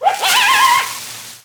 foxpanic.wav